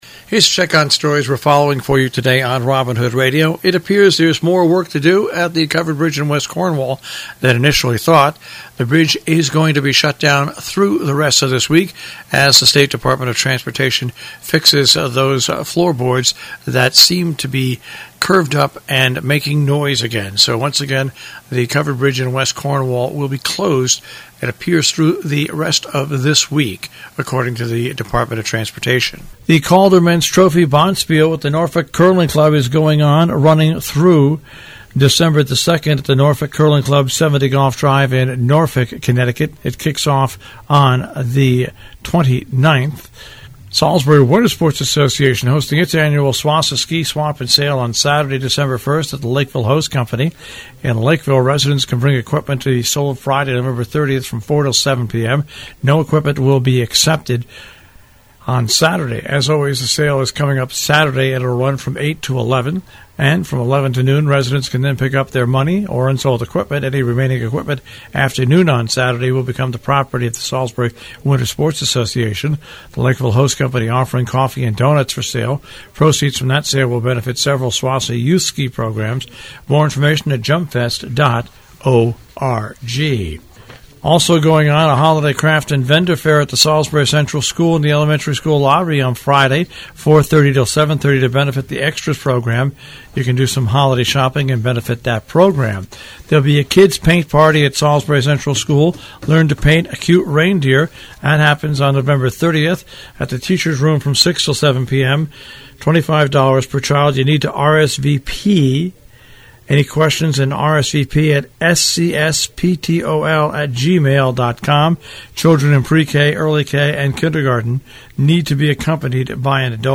covers news and events in the Tri-State Region on The Breakfast Club on Robinhood Radio